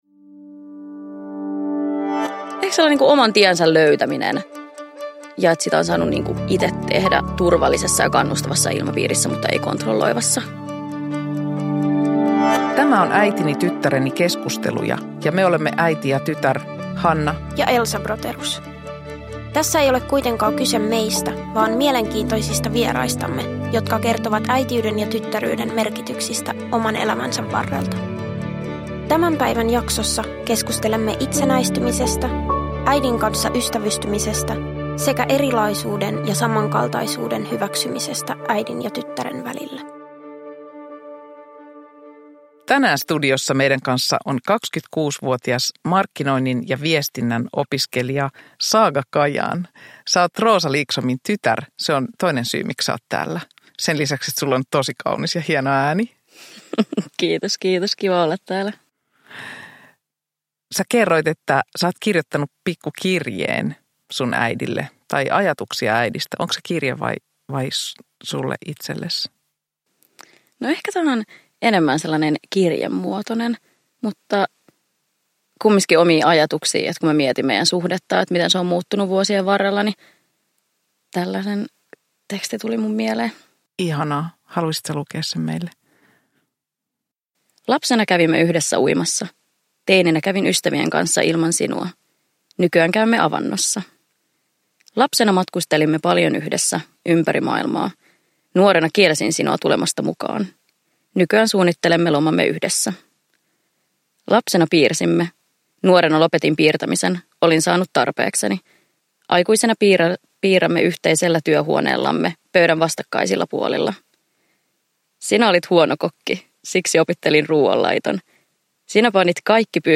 Äitini, tyttäreni -keskusteluja osa 6 – Ljudbok
Avointa ja koskettavaa keskustelua